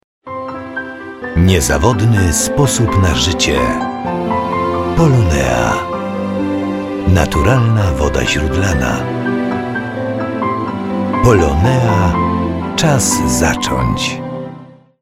reklama radiowa #4